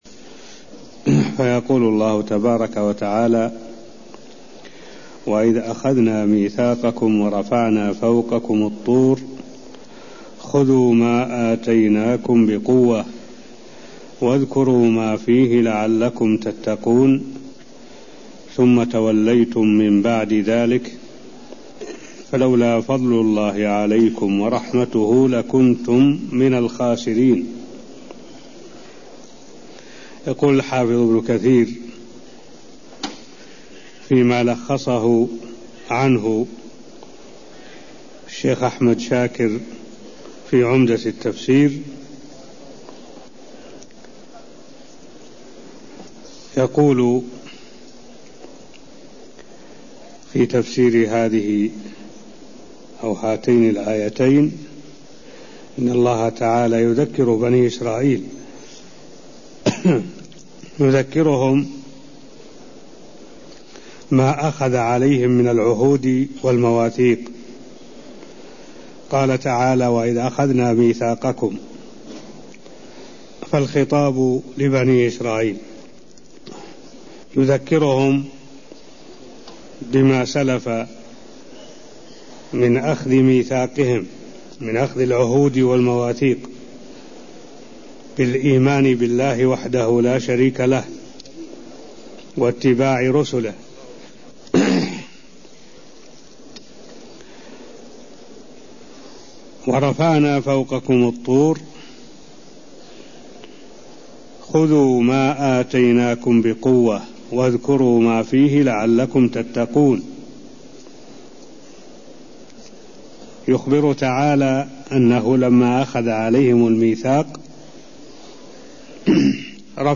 المكان: المسجد النبوي الشيخ: معالي الشيخ الدكتور صالح بن عبد الله العبود معالي الشيخ الدكتور صالح بن عبد الله العبود فسير سورة البقرة آية63ـ64 (0038)